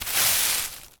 sweeping_broom_leaves_stones_08.wav